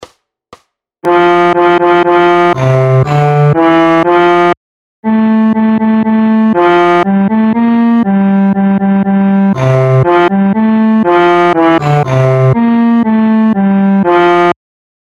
Aranžmá Noty na violoncello
Hudební žánr Lidovky